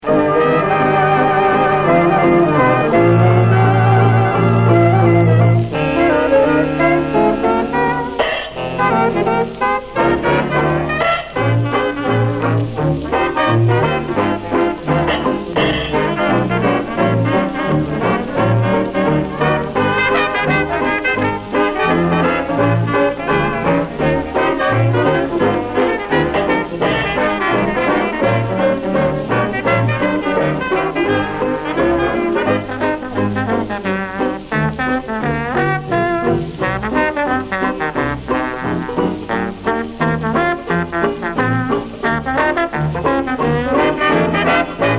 Jazz　France　12inchレコード　33rpm　Mono